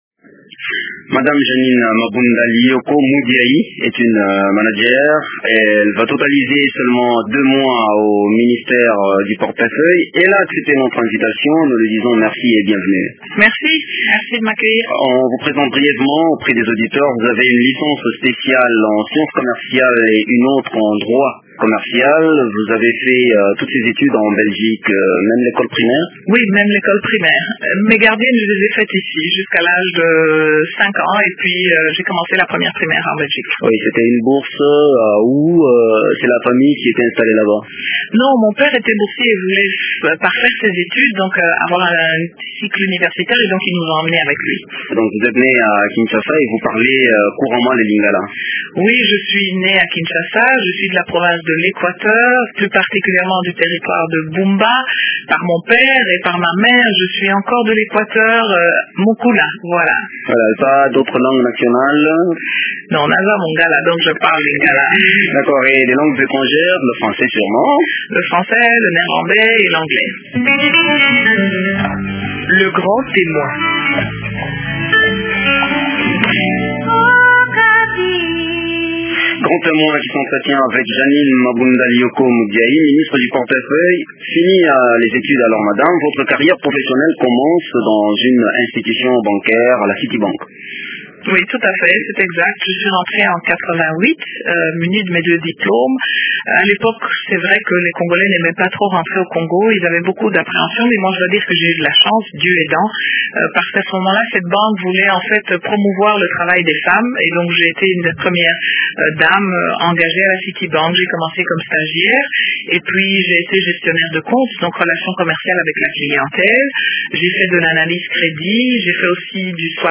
Elle nous raconte son parcours dans un entretien